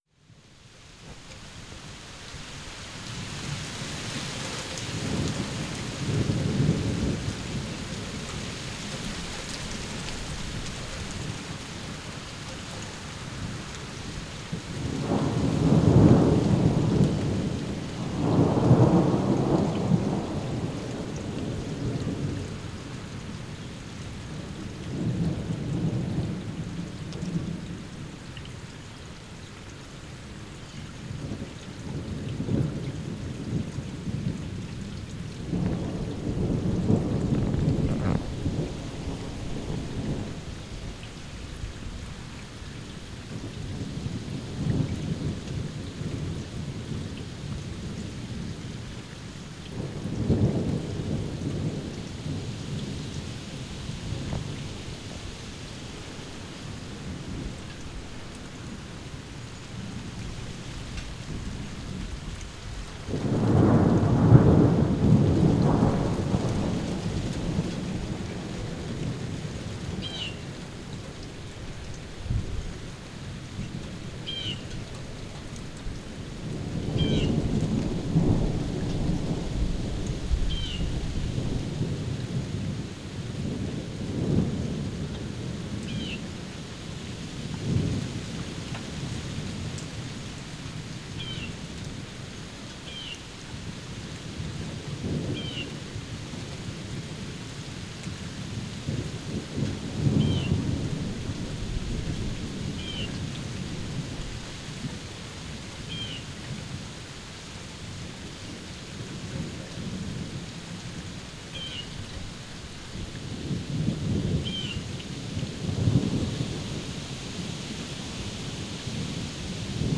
july-thundershower.wav